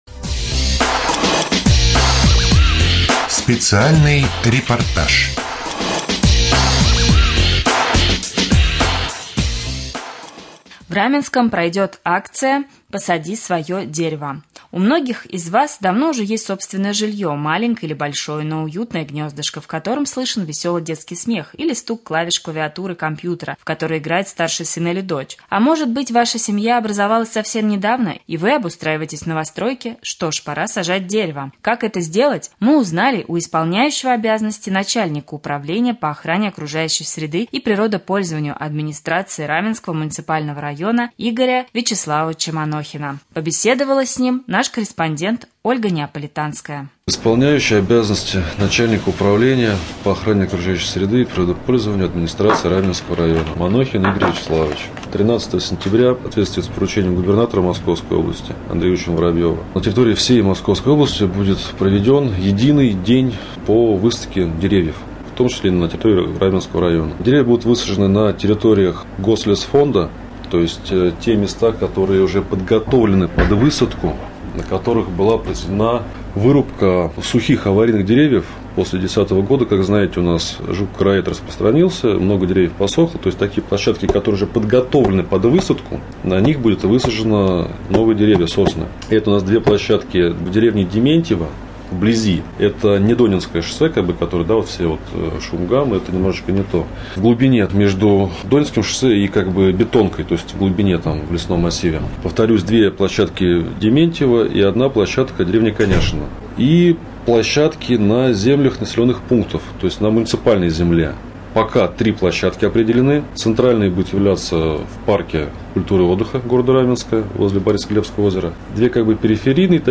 2.Рубрика «Специальный репортаж». В Раменском пройдет акция: Посади свое дерево!